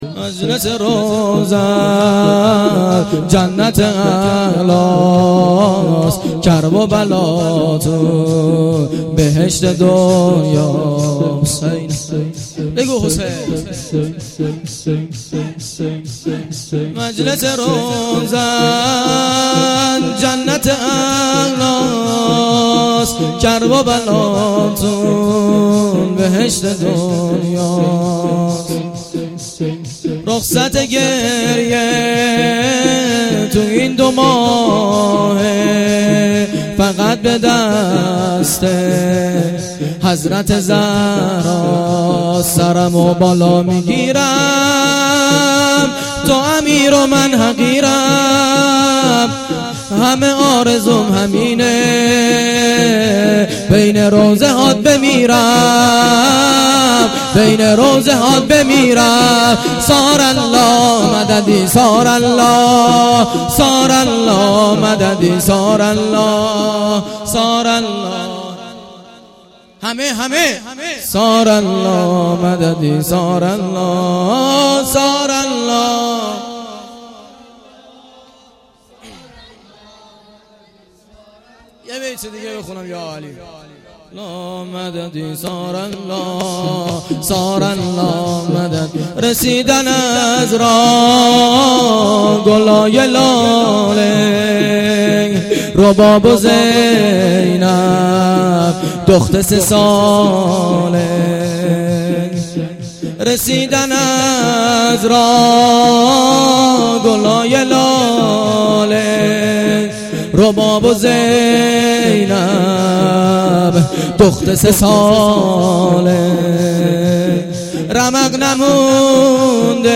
گزارش صوتی جلسه هفتگی 28 محرم الحرام
شور
shoor.mp3